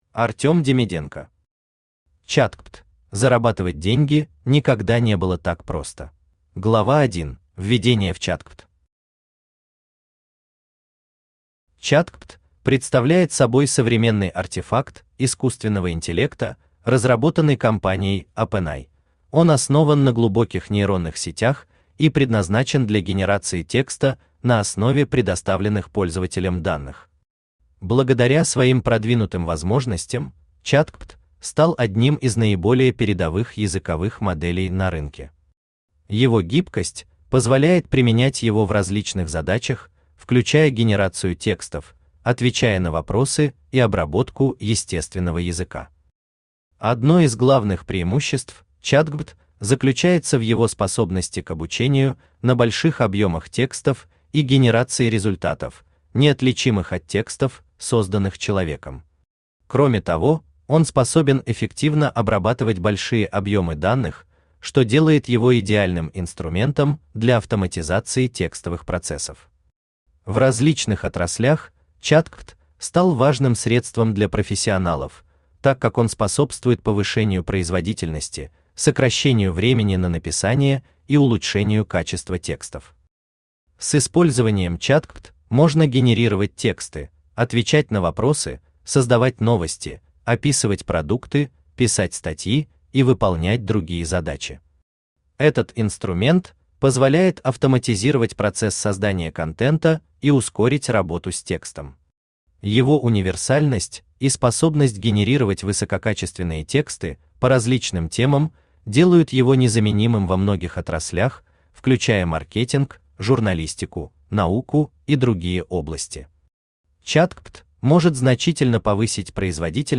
Аудиокнига ChatGPT. Зарабатывать деньги никогда не было так просто | Библиотека аудиокниг
Зарабатывать деньги никогда не было так просто Автор Артем Демиденко Читает аудиокнигу Авточтец ЛитРес.